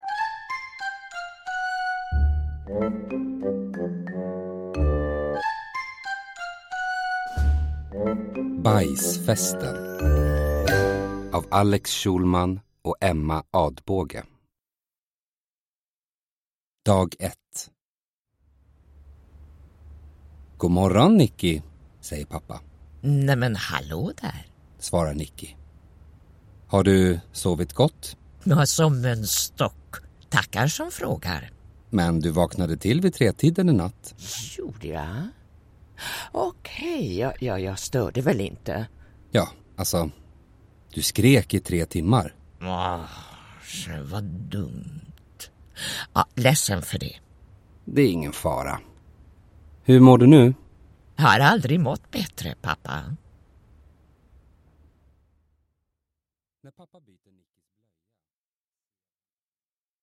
Bajsfesten – Ljudbok – Laddas ner